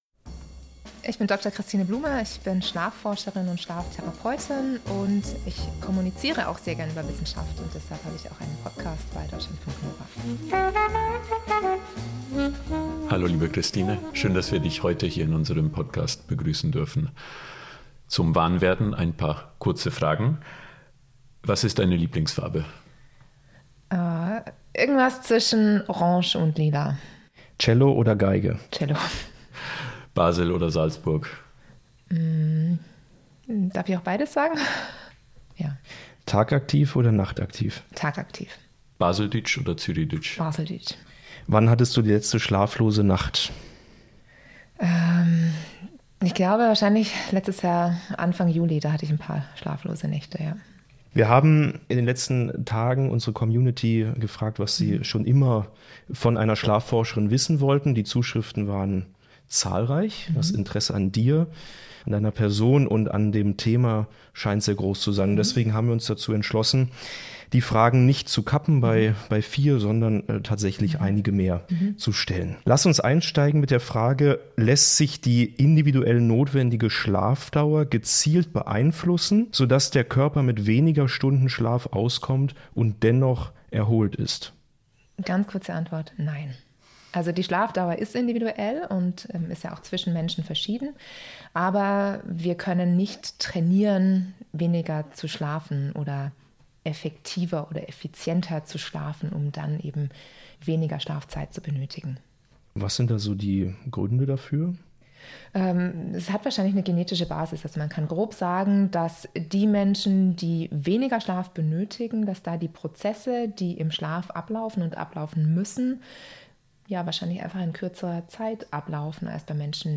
Das Interview wurde am 28.02.2025 aufgezeichnet.